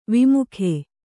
♪ vimukhe